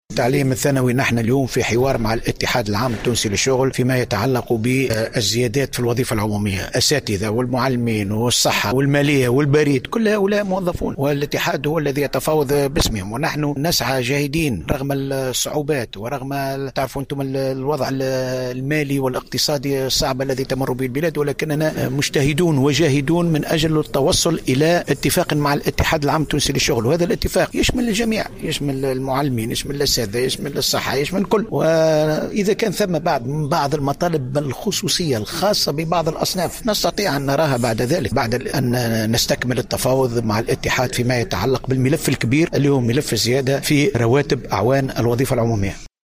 قال وزير الشؤون الاجتماعية محمد الطرابلسي في تصريح لمراسلة الجوهرة "اف ام" اليوم الجمعة إن الاتحاد العام التونسي للشغل هو الذي يتفاوض ويتحدث بإسم الأساتذة والمعلمين و أعوان الصحة والمالية و البريد.
وشدد الطرابلسي على هامش الإحتفال باليوم الوطني للأرشيف والاحتفاء بالذكرى السبعين للإعلان العالمي لحقوق الإنسان واليوم العالمي للغة العربية على أن الوزارة تعمل جاهدة حاليا على التوصل إلى اتفاق مع الإتحاد العام التونسي للشغل فيما يتعلق بالزيادة في الأجور في الوظيفة العمومية رغم الصعوبات المالية التي تمر بها البلاد.